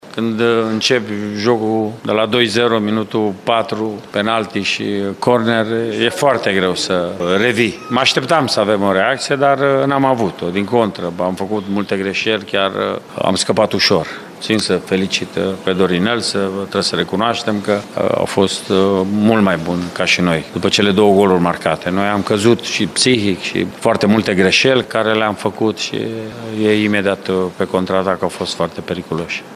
La final, antrenorul UTA-ei, Mircea Rednic, a recunoscut că Oțelul a fost superioară în joc astăzi: